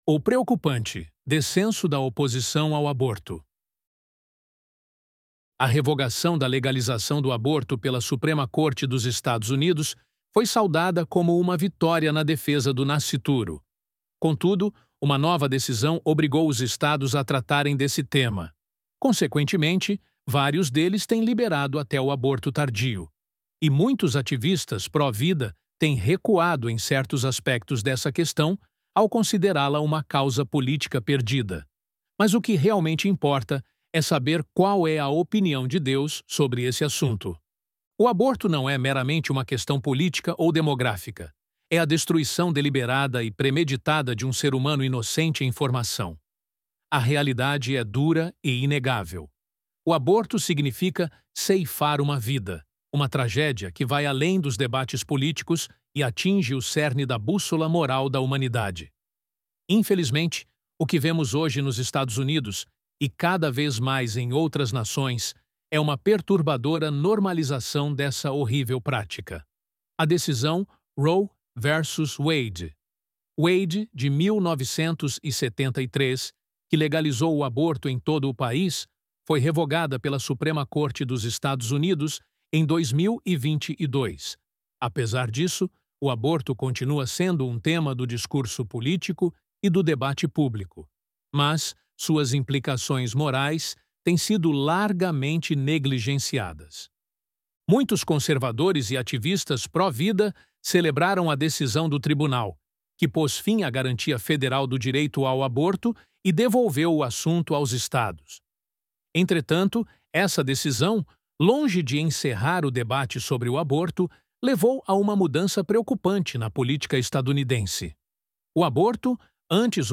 ElevenLabs_O_Preocupante_Descenso_da_Oposição_ao_Aborto.mp3